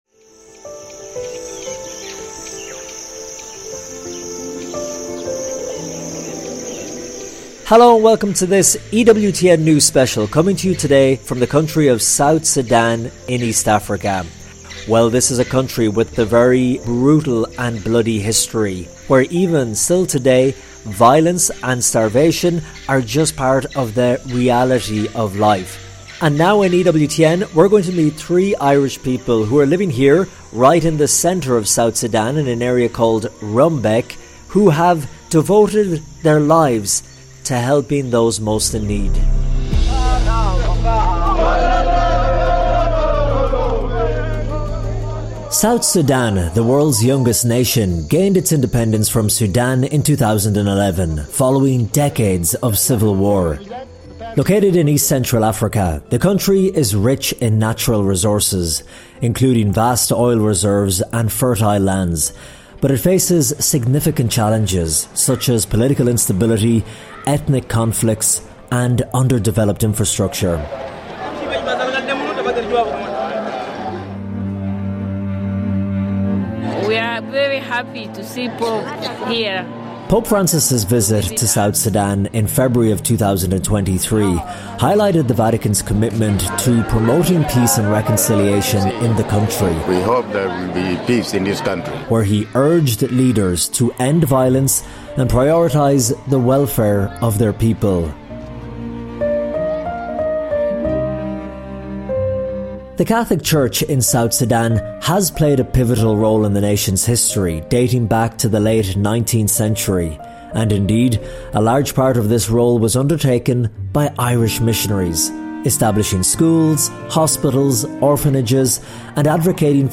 An EWTN News special in partnership with ACI Africa.